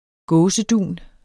Udtale [ ˈgɔːsəˌduˀn ]